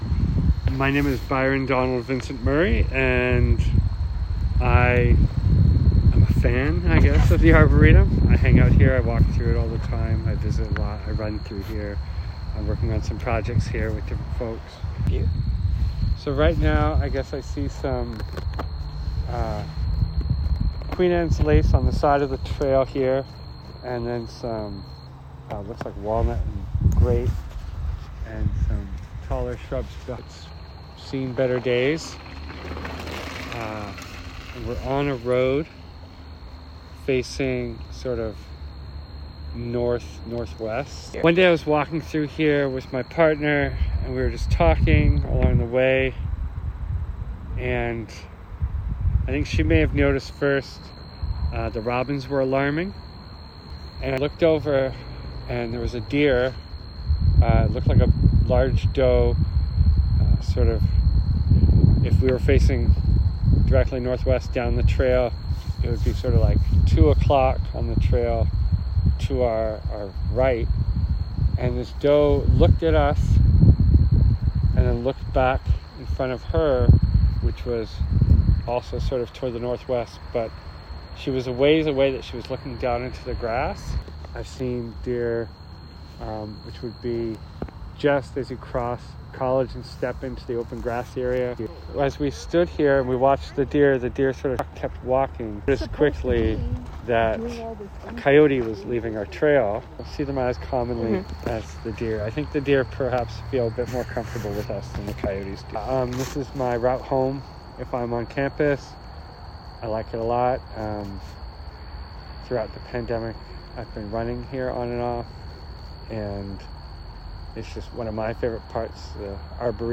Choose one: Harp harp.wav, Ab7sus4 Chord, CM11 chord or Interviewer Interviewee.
Interviewer Interviewee